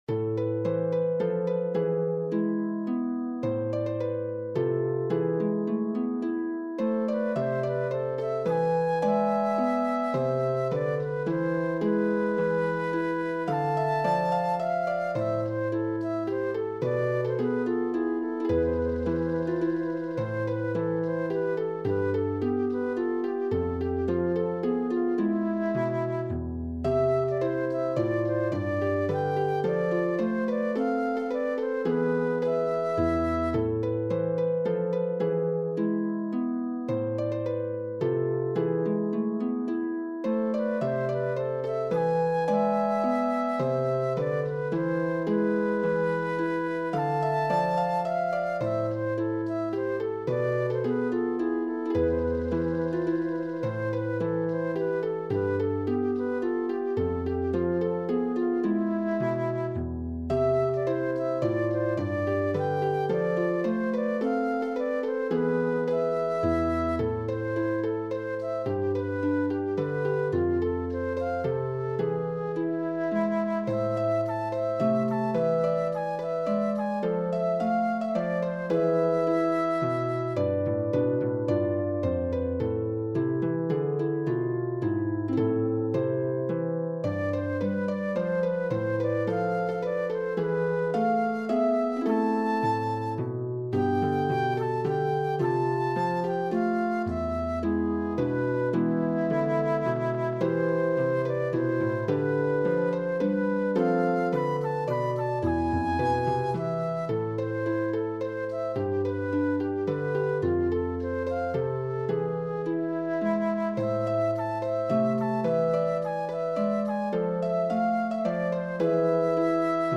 for flute and pedal harp
joyful
in A minor